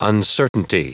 Prononciation du mot uncertainty en anglais (fichier audio)
Prononciation du mot : uncertainty